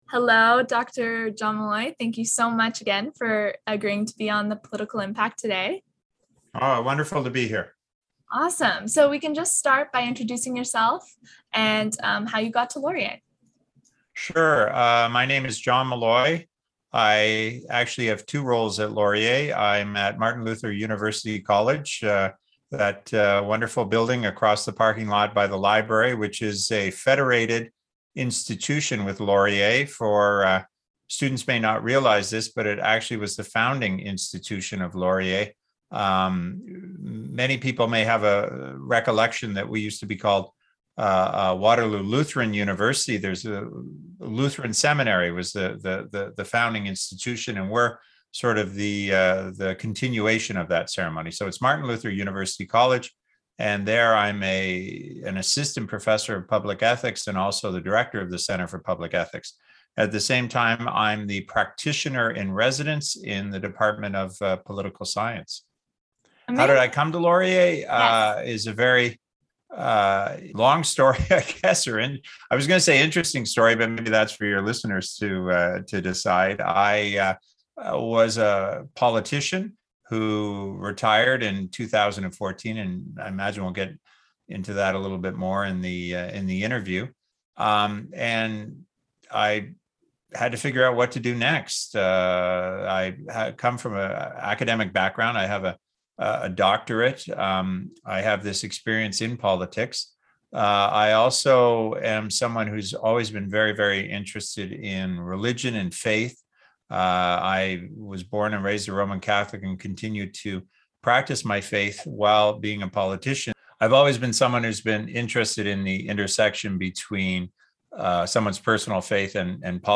The Political Impact Ep. 3 - Interview